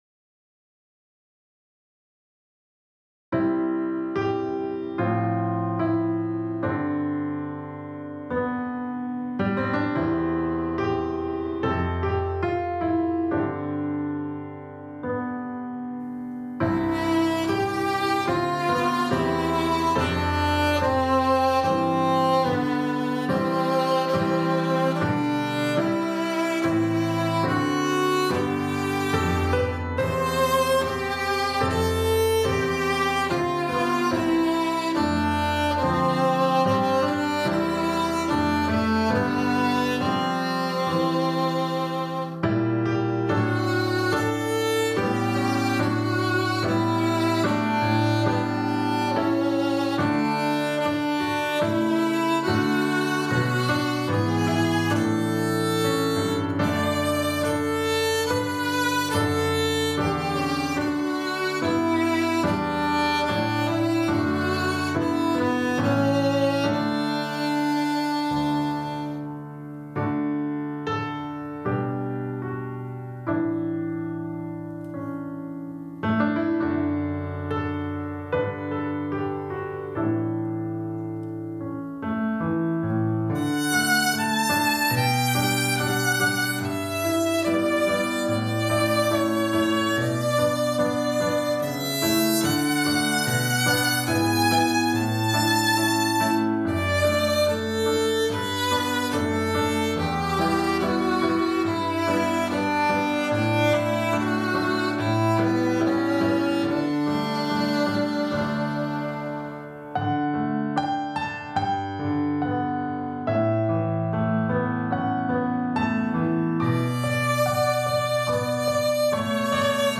hymn
This a gentle and lyrical arrangement for strings.